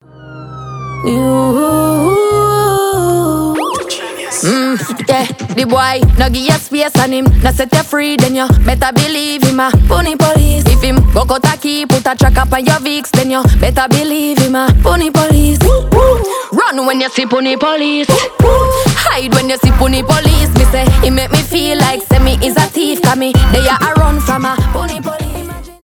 поп , rnb
dancehall , afrobeat